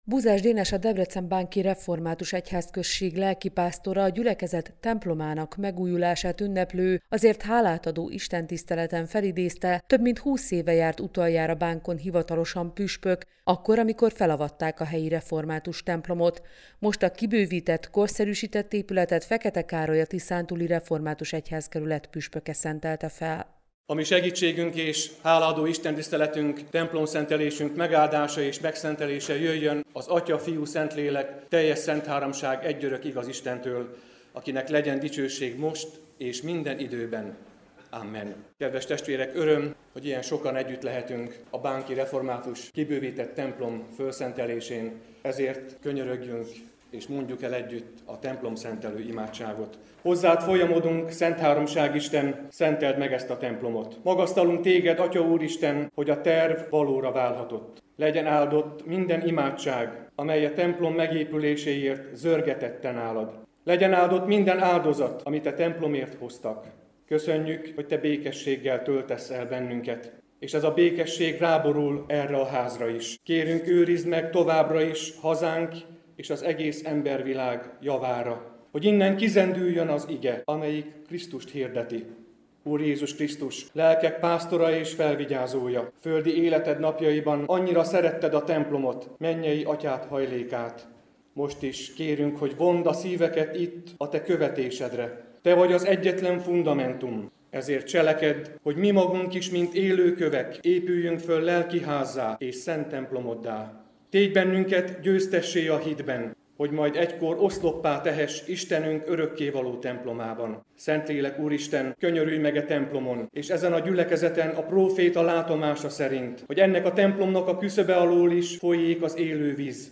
Templomszentelő hálaadó istentisztelet Bánkon
A templomszentelő hálaadó alkalom a meghívottak köszöntőivel folytatódott.